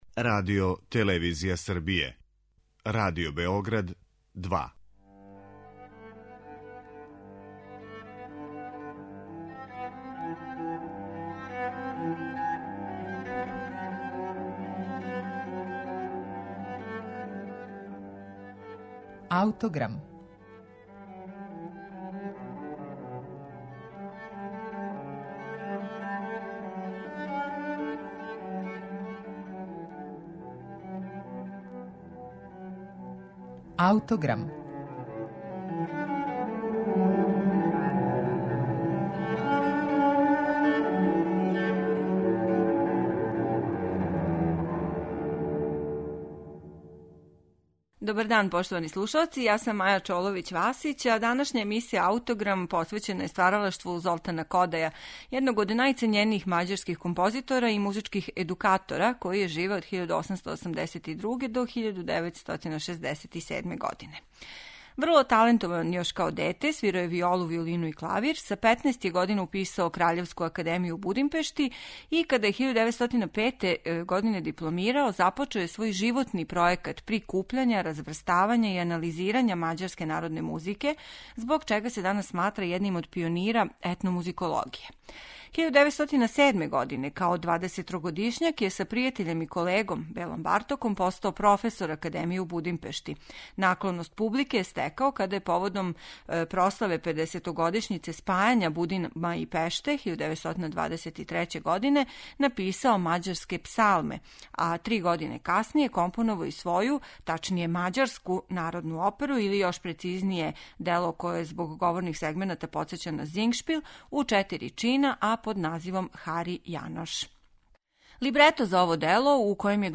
Шестоставачну свиту из опере изводи Кливлендски оркестар. Диригент је Џорџ Сел.